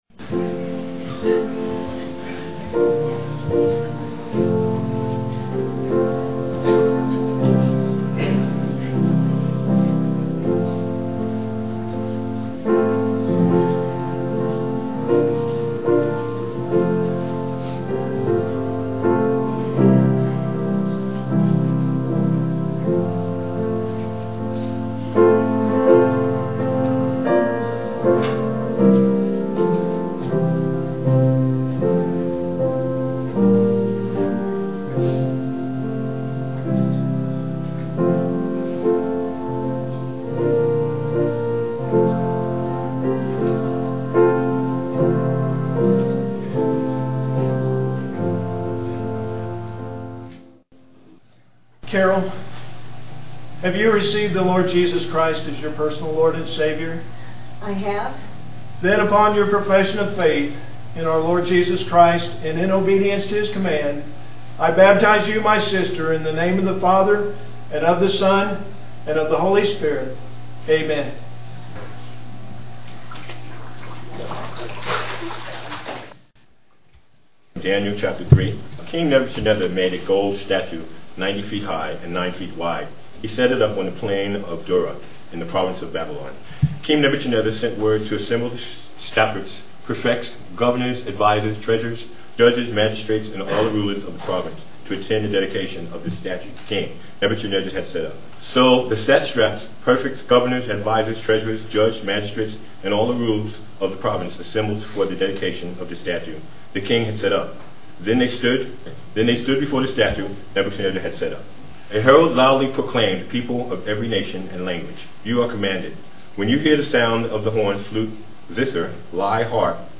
Piano and organ duet